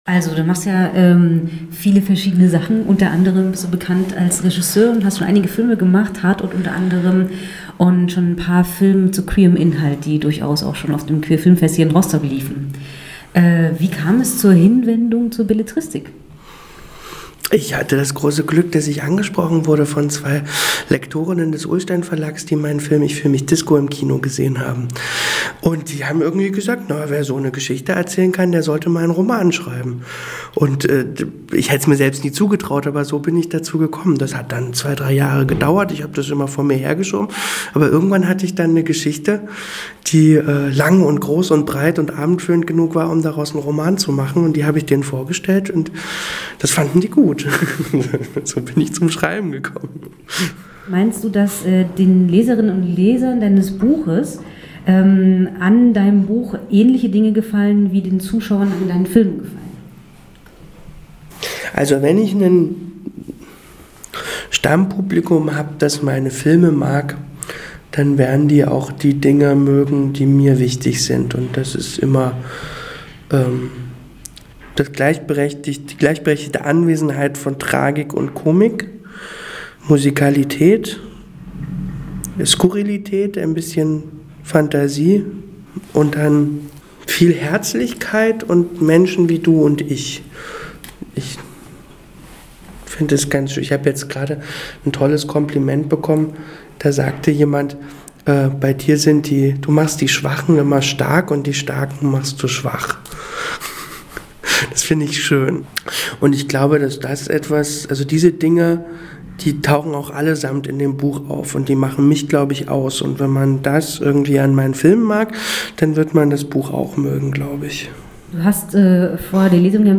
Interview mit Axel Ranisch